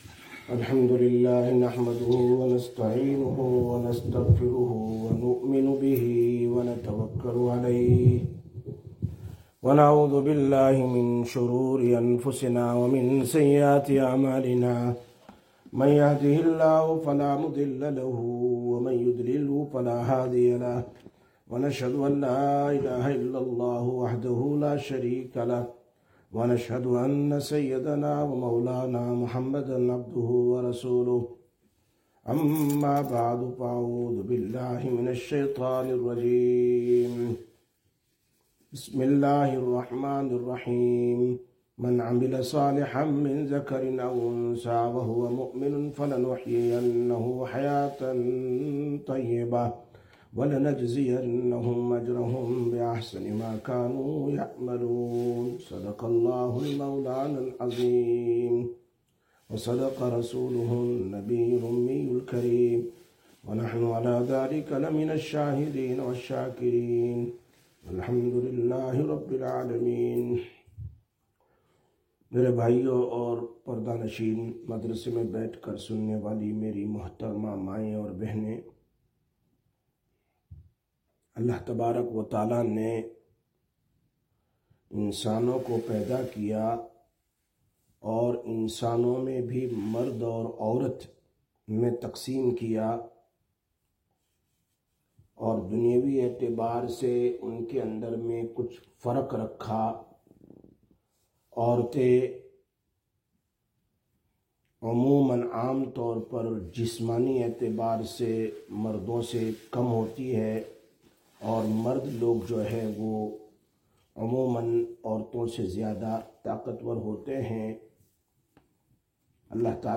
04/09/2024 Sisters Bayan, Masjid Quba